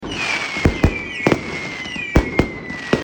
烟花.MP3